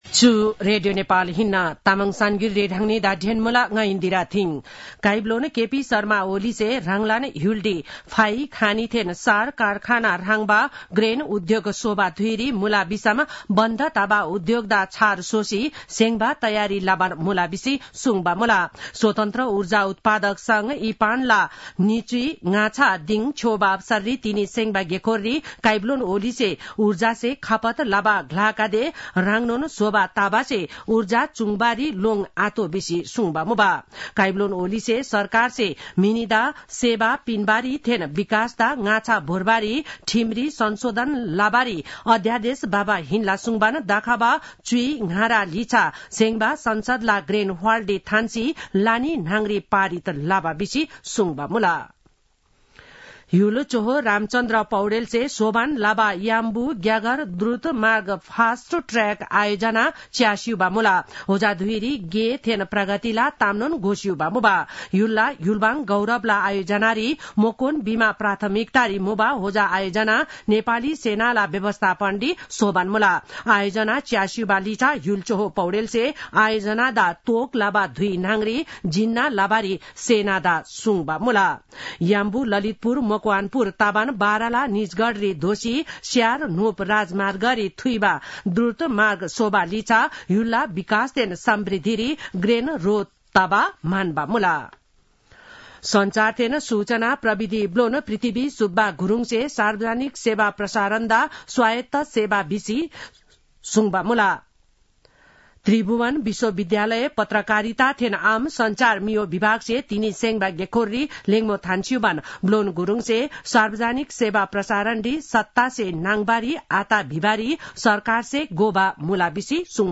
तामाङ भाषाको समाचार : ५ माघ , २०८१